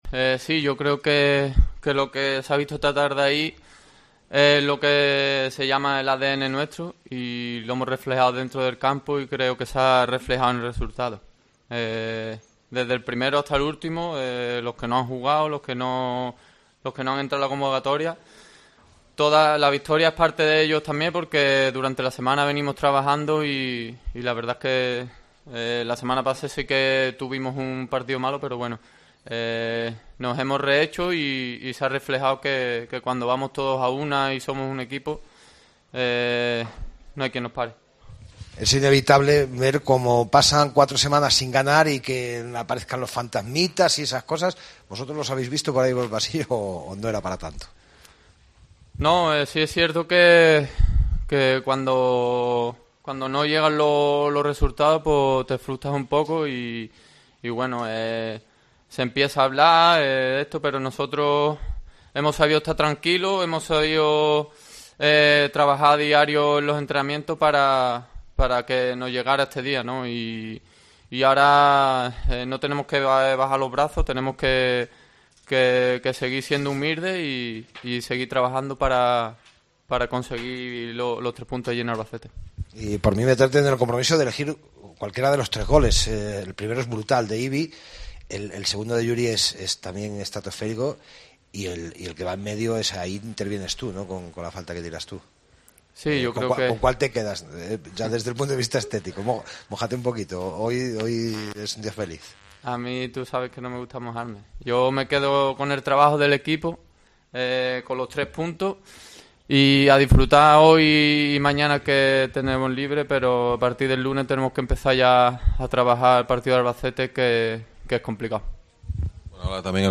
POSTPARTIDO
Escucha aquí las palabras de los dos jugadores blanquiazules